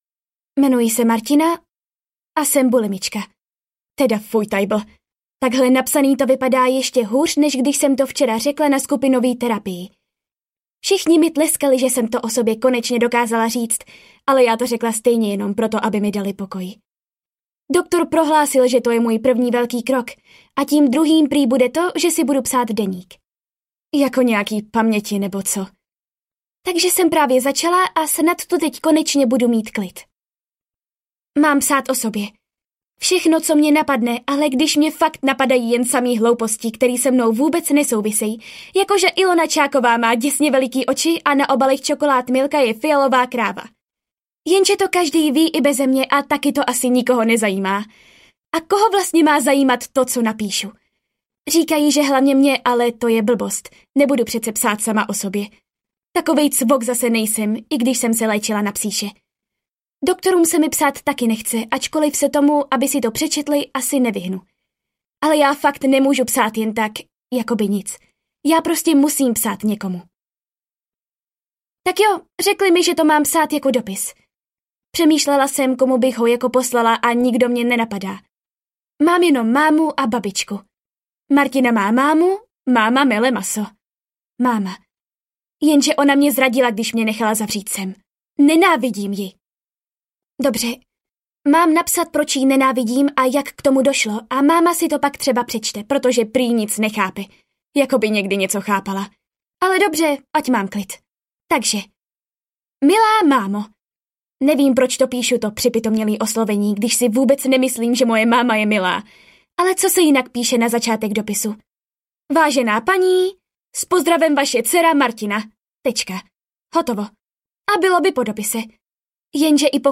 Jmenuji se Martina audiokniha
Ukázka z knihy